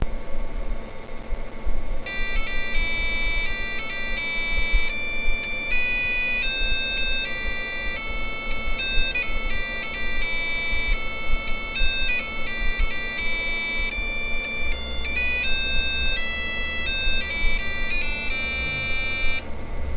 This program plays (well, really, "beeps") Christmas carols and shows you the text of the carol.